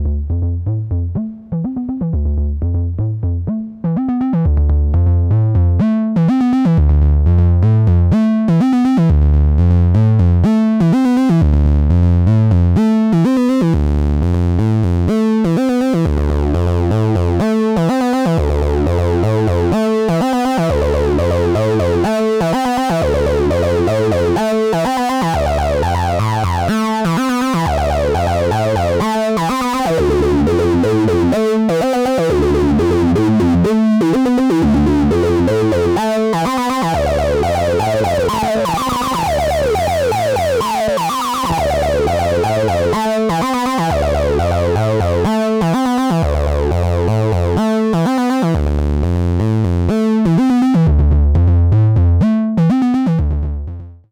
Just tweaking a simple sequence with the Big Muff hooked up to the SB-1.